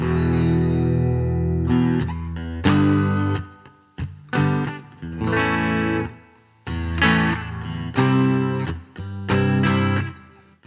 E吉他节奏90BPM Em G A C
描述：90 BPM，但可以很好地翘起到120或130。和弦是Em G A C。大约还有10分贝的净空，所以你有空间来提升它。
Tag: 90 bpm Pop Loops Guitar Electric Loops 1.80 MB wav Key : E